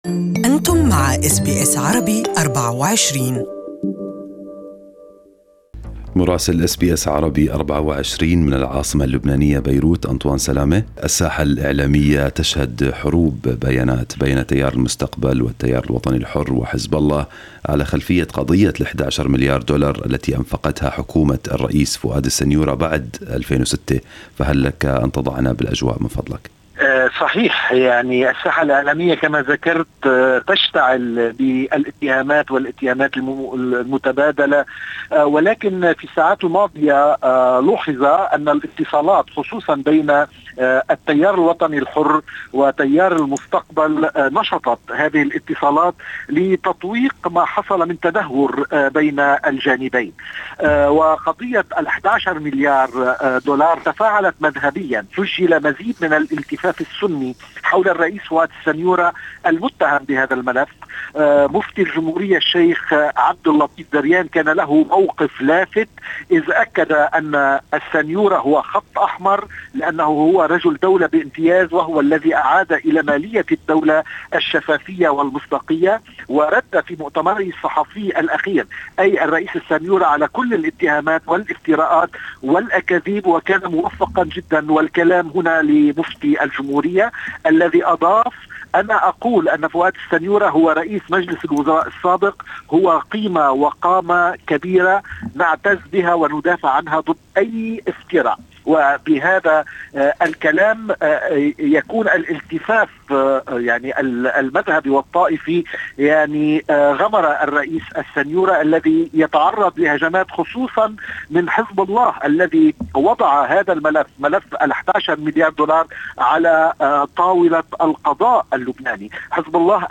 Our correspondent in Beirut has the details